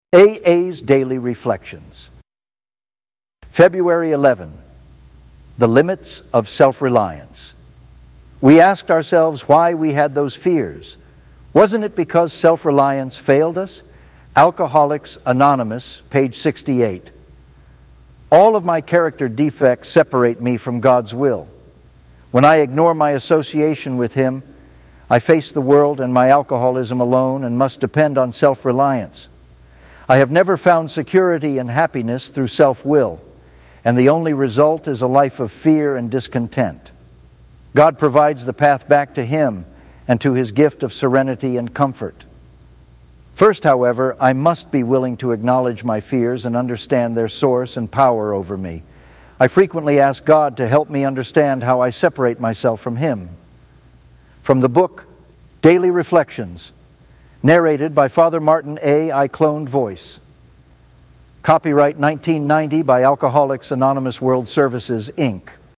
Cloned Voice.